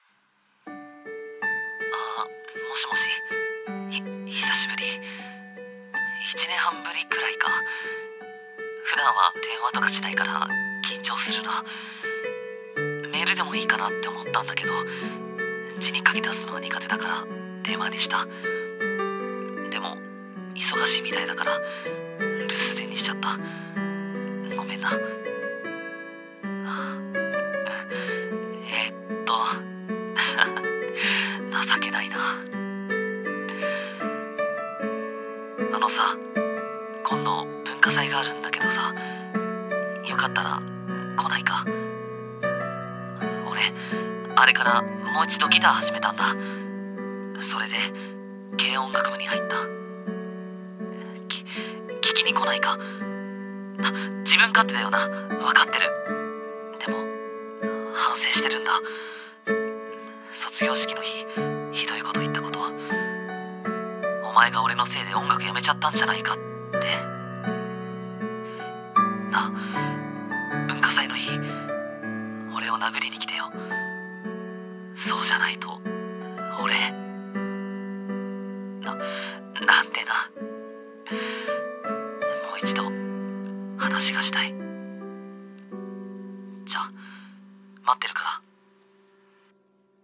【声劇】 話がしたいんだ。